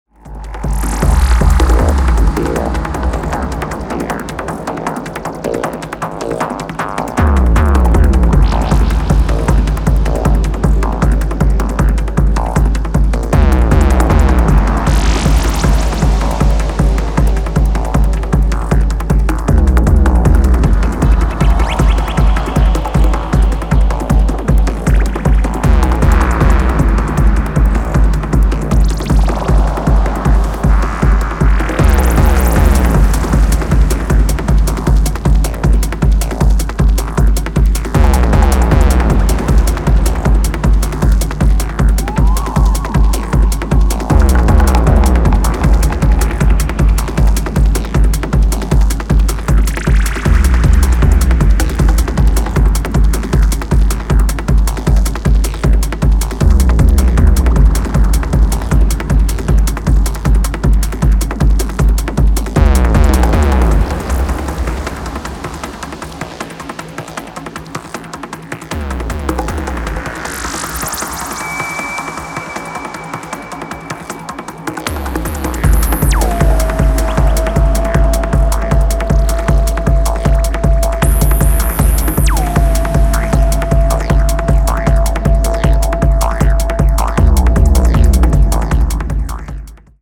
初期のスローモーなグルーヴからは装いを変えて、今回は速いです。
持ち味のトライバルなパーカッションも相まって非常にグルーヴィー、強力無比です。